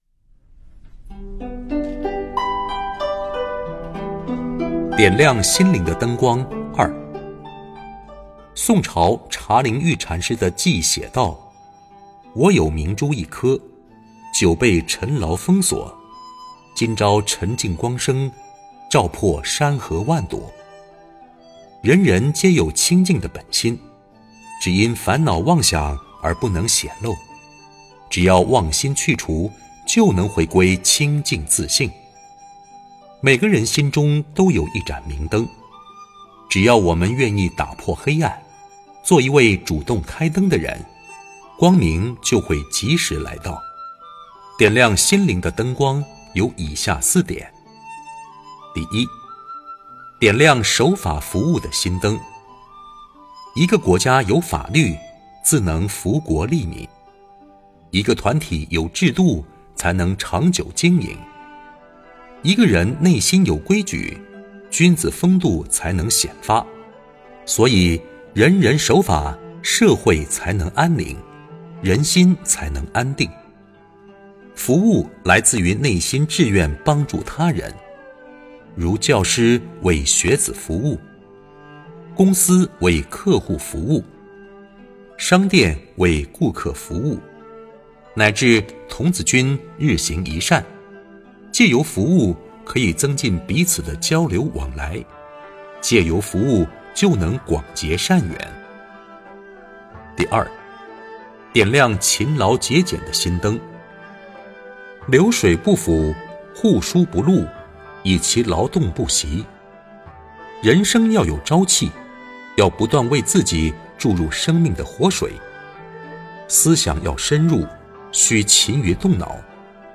佛音 冥想 佛教音乐 返回列表 上一篇： 68.点亮心灵的灯光(一